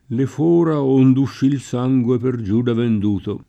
le f1ra 1nd ušši l S#jgUe per J2da vend2to] (Dante) — tutta per l’-o- chiuso la Tosc., tanto nel sost. quanto nelle voci rizotoniche di forare; tutta per l’-o- aperto Roma; incerta o divisa la restante It. mediana — un -o-, questo, che non viene direttam. dall’-o- del lat. forare (dal quale, essendo breve, si sarebbe generato in it. un dittongo -uo-), ma è ricavato in it. da forare e dalle altre voci verbali accentate sulla desinenza (una situaz. in cui con altri verbi si sono avuti esiti disparati: cfr. dimorare; domare; folla) — di pari passo con foro le altre voci della famiglia: forare; perforare; sforare; straforare; straforo; traforare; traforo